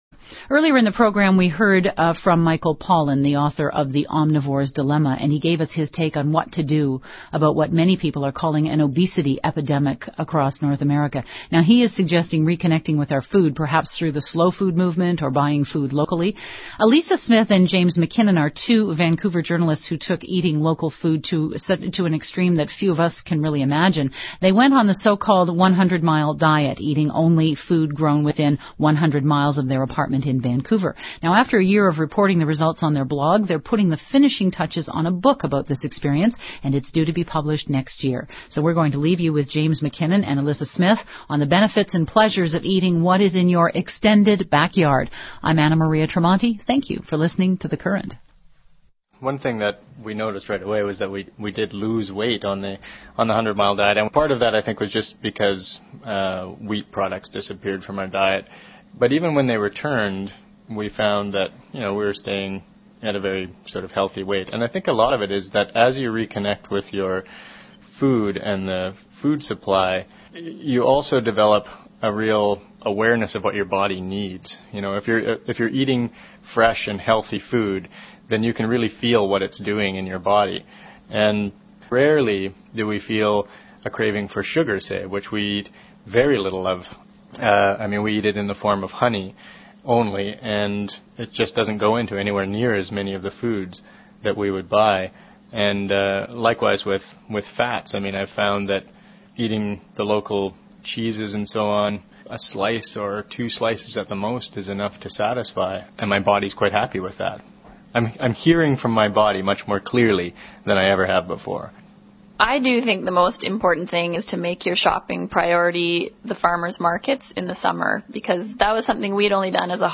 The following is extracted and adapted from the May 5, 2006 The Current program.